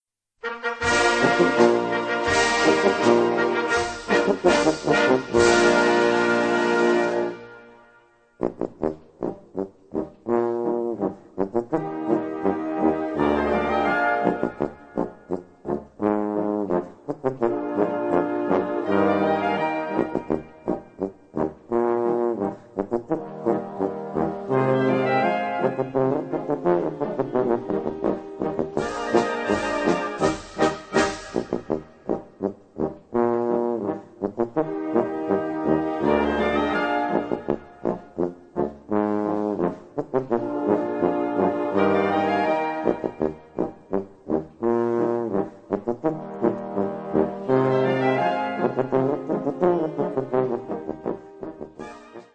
Solo-Polka für Tuba in Eb oder B
Blasorchester